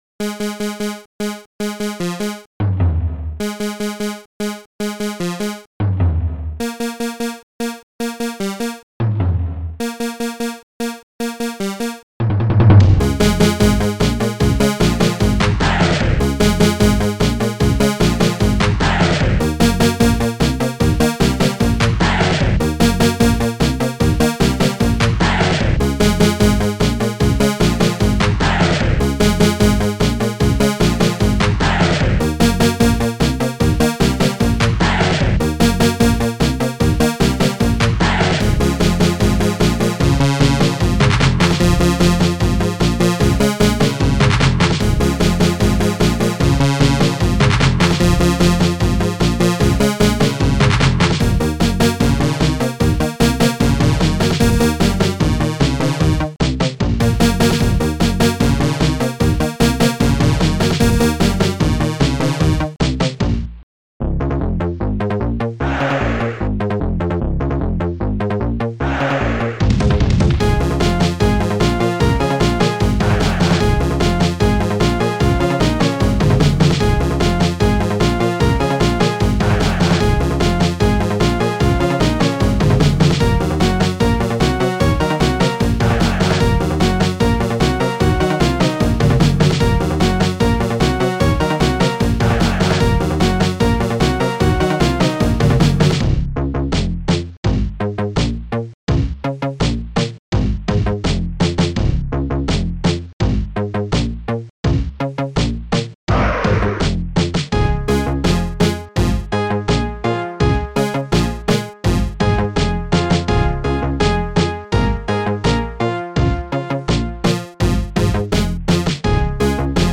Protracker Module
st-02:snare24 st-02:bassdrum23 st-02:Perc-Bongo st-05:hey3 st-03:Hapsi st-02:victoryclaps3 st-03:digdug2 st-06:CordPiano